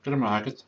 gˠərˠəmˠagˠət̪/, at least in Donegal.